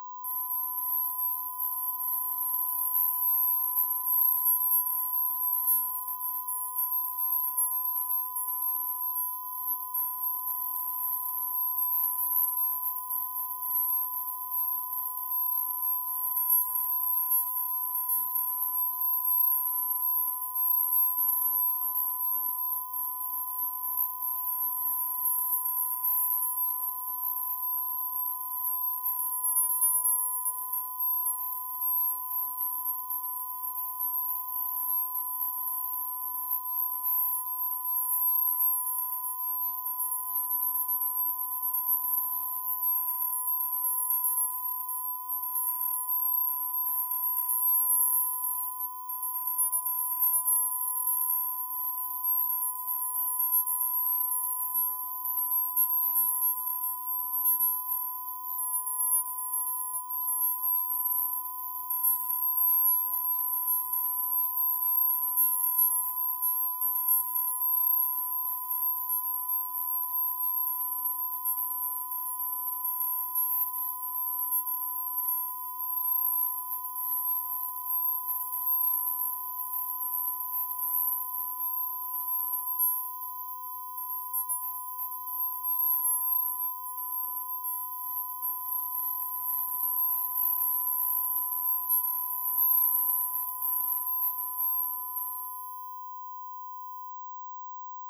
ici un second fichier audio avec un bruit haute fréquence, artificiellement ajouté.
noisy_inverted.flac